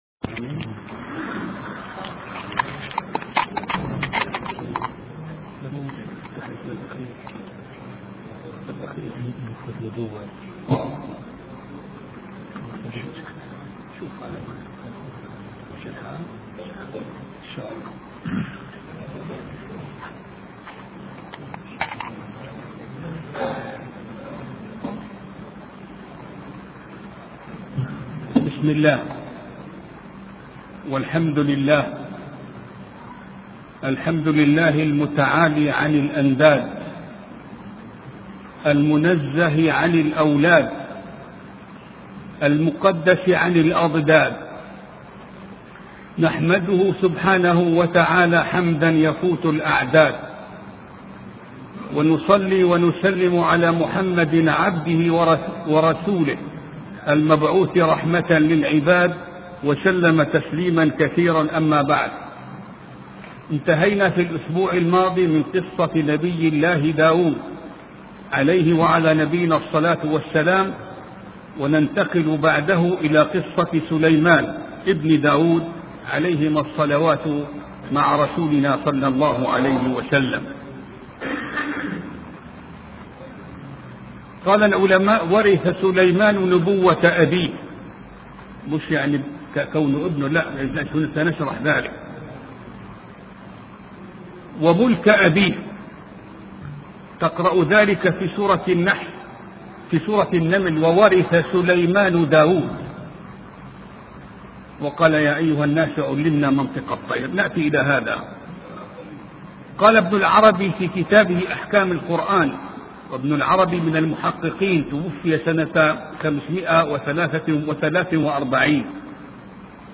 سلسلة محاضرات في قصة سليمان علية السلام